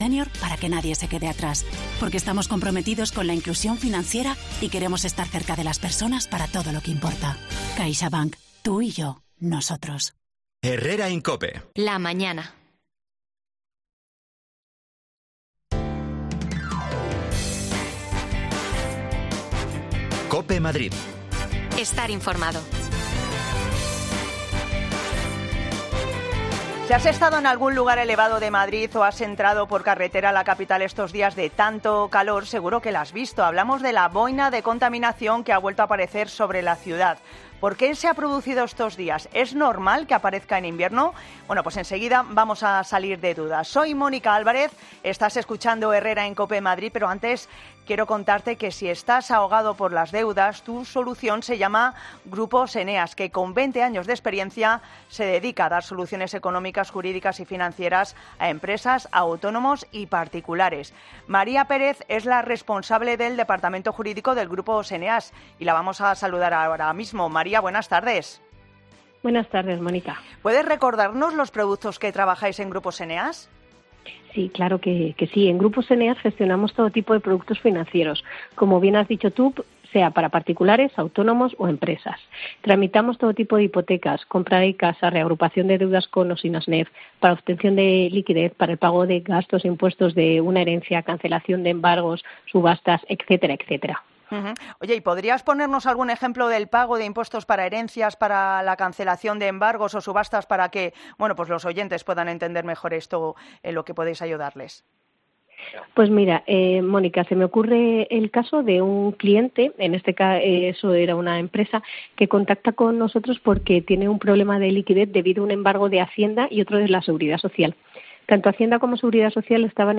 AUDIO: Uno de los grandes beneficiados de este buen tiempo es el sector de la hosteleria... Lo comprobamos saliendo a la calle y hablando con los...